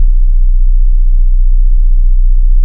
XXL BASS 2.wav